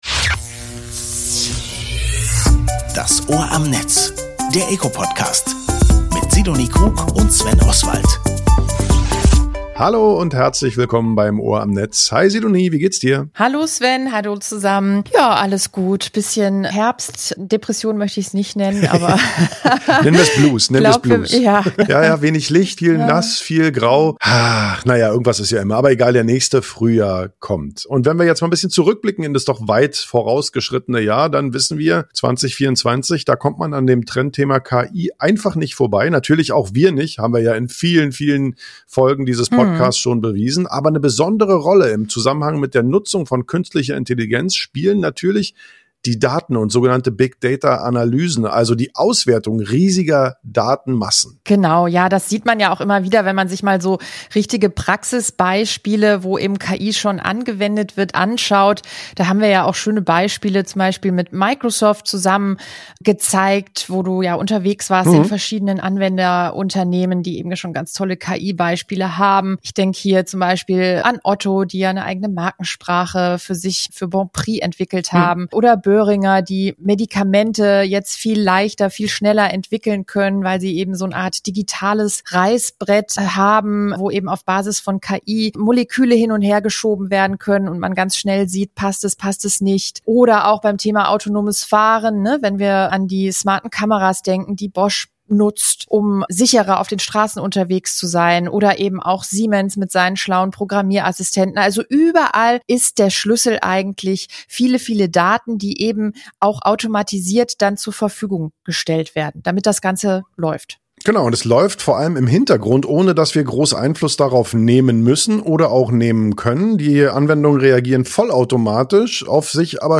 Robin Mesarosch , Mitglied des Deutschen Bundestages und Berichterstatter der SPD-Fraktion für die Verwaltungsdigitalisierung, erläutert, wo Deutschland bei der digitalen Verwaltung steht, wo KI hier Einsatz finden kann und welche Maßnahmen darüber hinaus für eine erfolgreiche digitale Verwaltung in Deutschland notwendig sind.